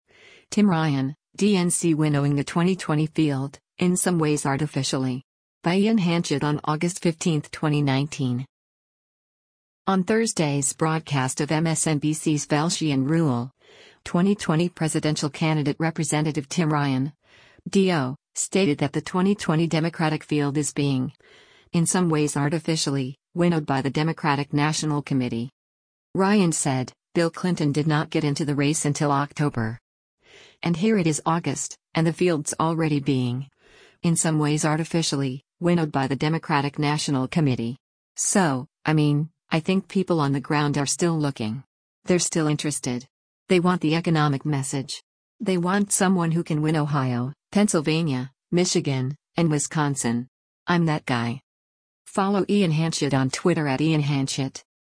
On Thursday’s broadcast of MSNBC’s “Velshi & Ruhle,” 2020 presidential candidate Representative Tim Ryan (D-OH) stated that the 2020 Democratic field is “being, in some ways artificially, winnowed by the Democratic National Committee.”